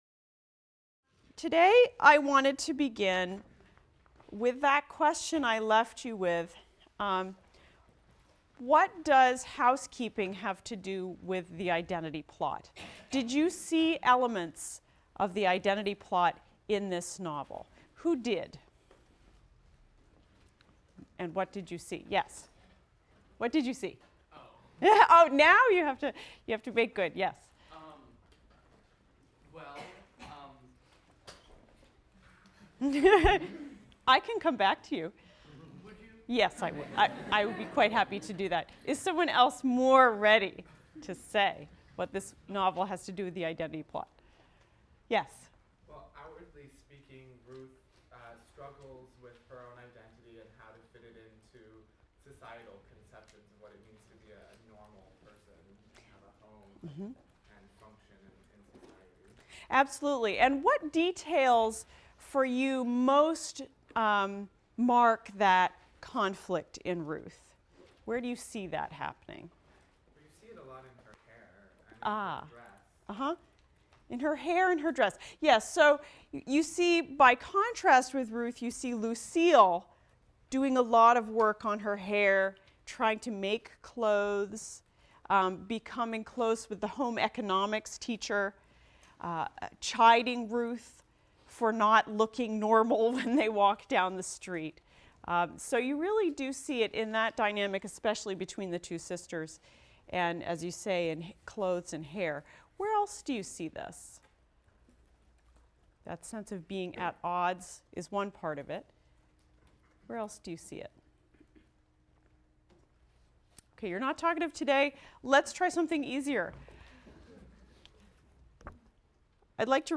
ENGL 291 - Lecture 15 - Marilynne Robinson, Housekeeping | Open Yale Courses